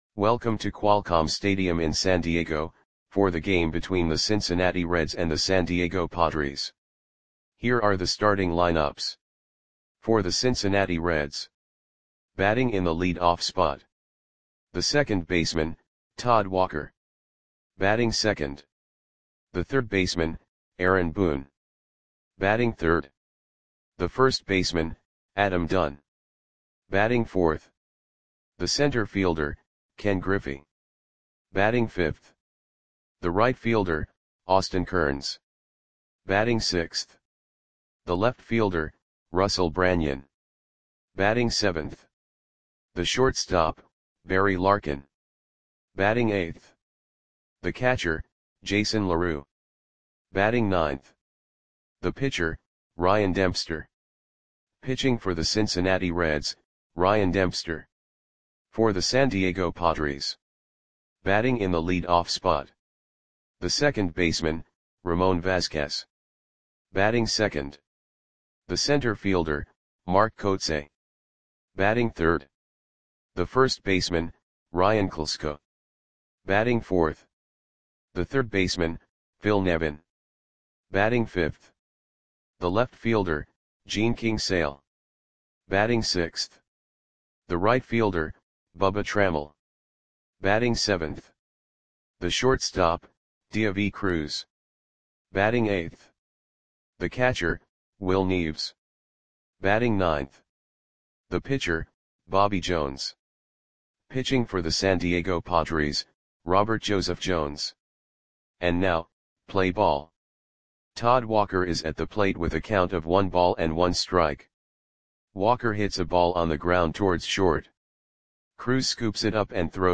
Audio Play-by-Play for San Diego Padres on August 4, 2002
Click the button below to listen to the audio play-by-play.